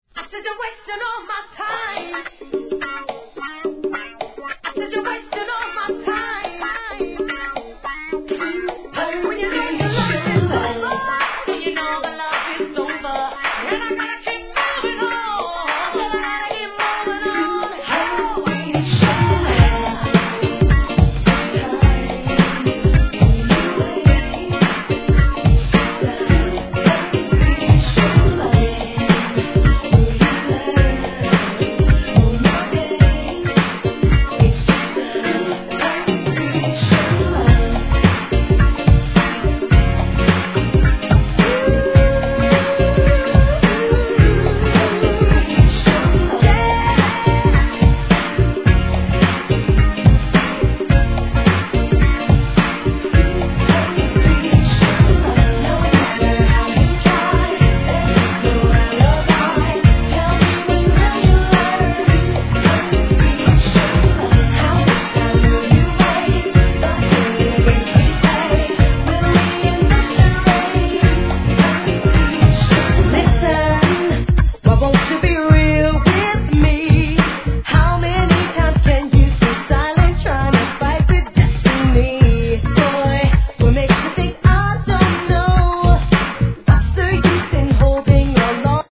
GARAGEテイストで跳ねたBEATにSAXが絡んでくる爽やかなR&B!